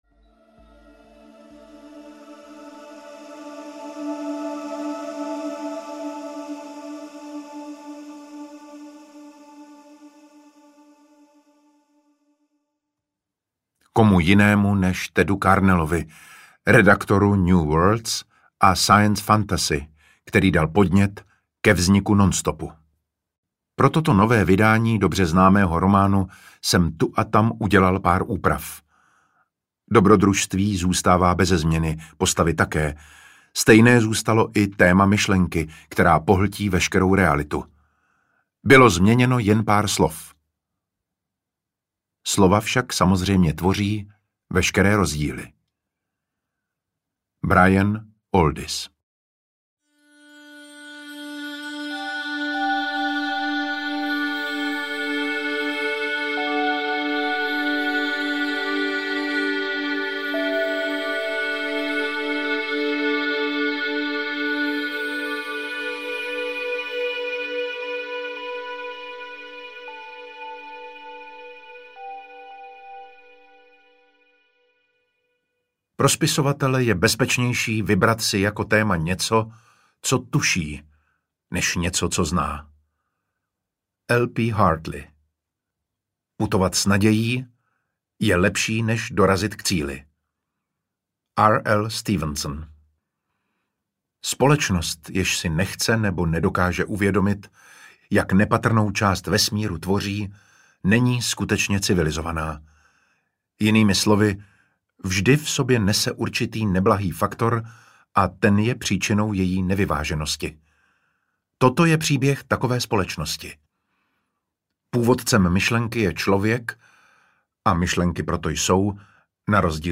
Ukázka z knihy
• InterpretDavid Matásek